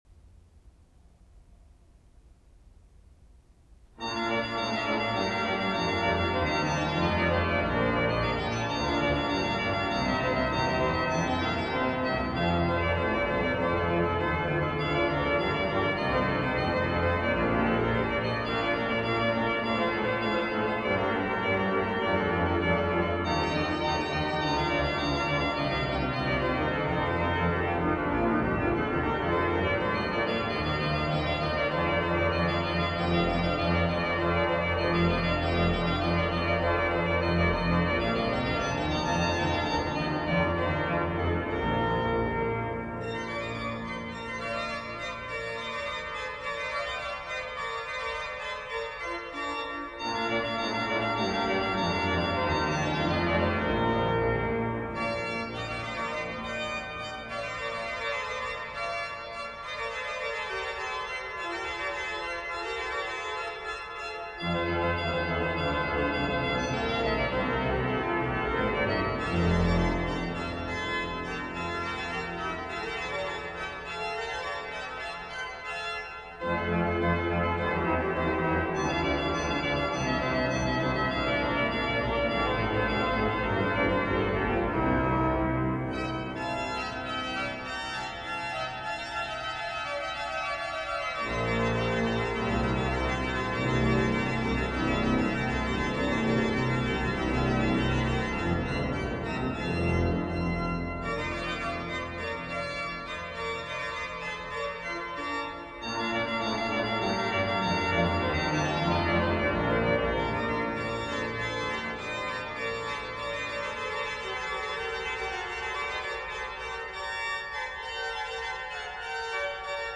Domorganist